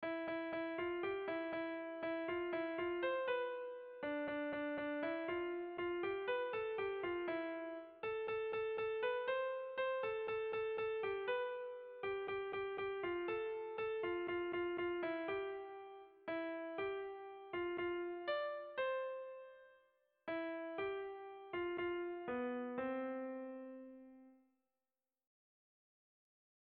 Bertsolaria
Zortziko txikia (hg) / Lau puntuko txikia (ip)